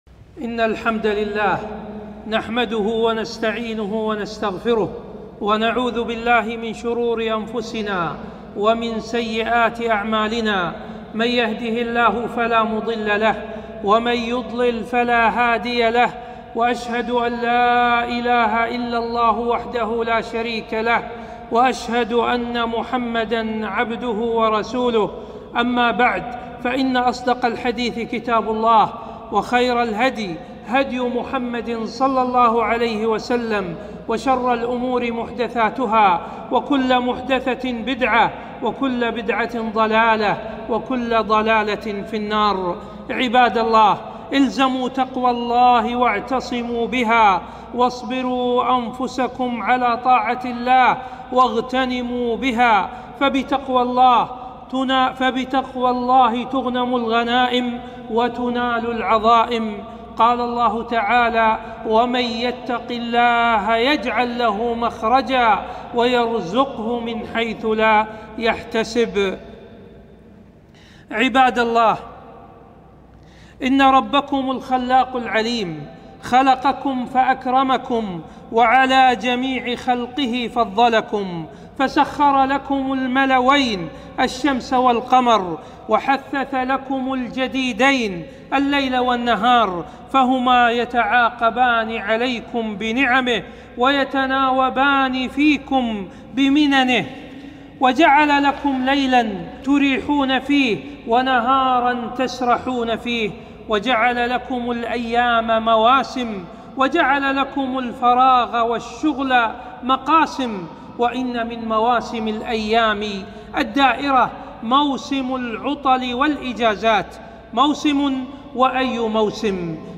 خطبة استغلال الإجازة والسفر للخارج